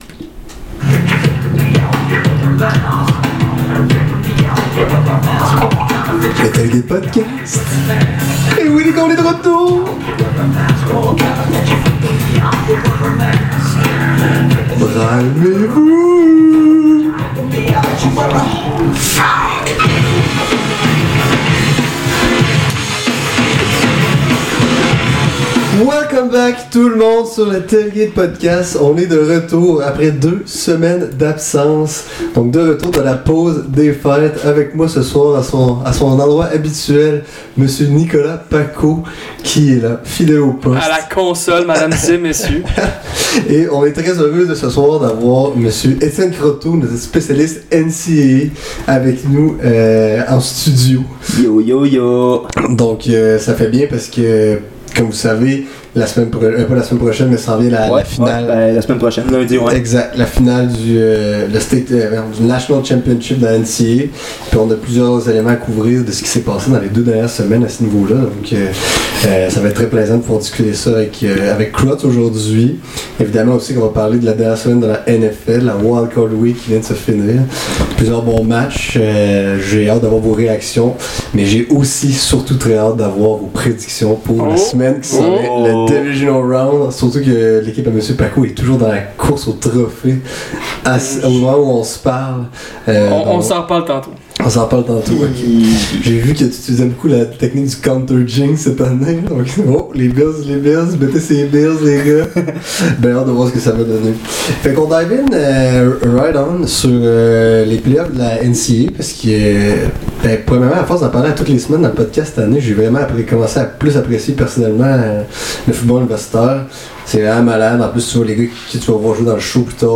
**** LES PROBLÈMES DE SON DURENT QUE QUELQUES MINUTES***